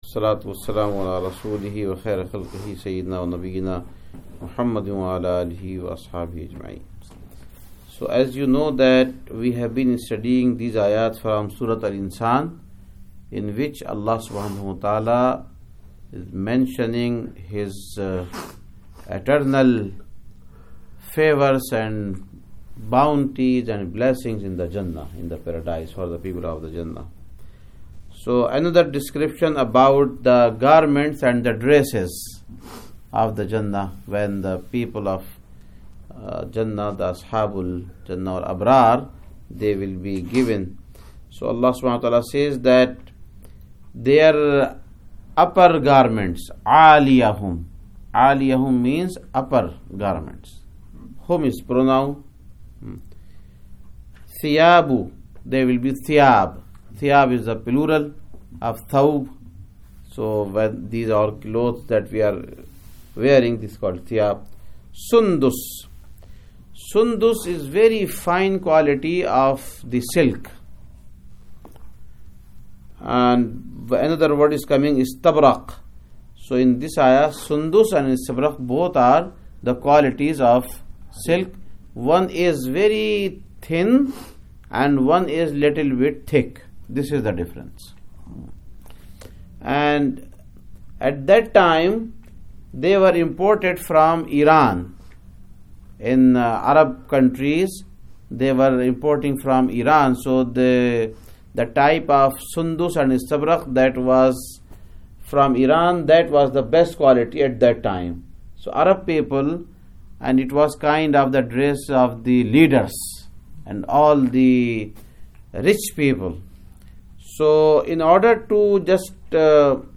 #29 Quran Class - Al-Insan Ayyat 21-25 Adult Quran Class conducted on 2015-03-29 at Frisco Masjid.